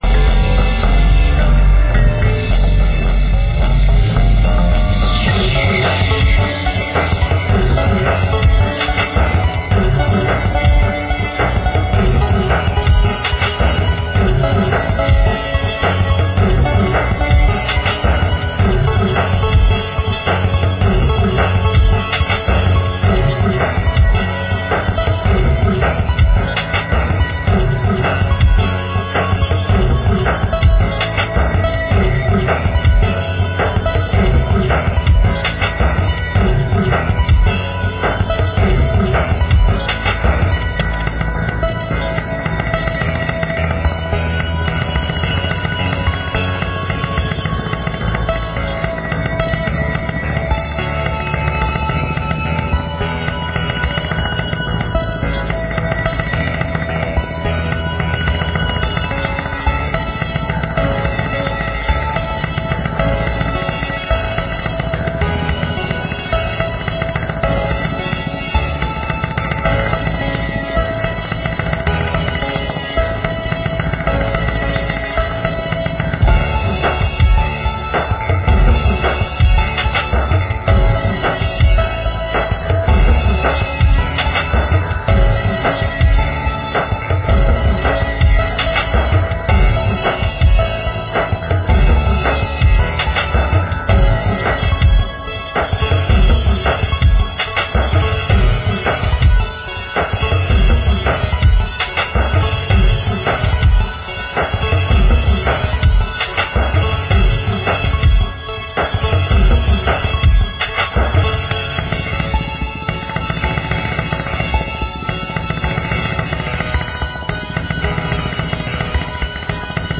Royalty Free Music for use in any type of
Heavy backing beat with some bell like percussion
and deep growling basses.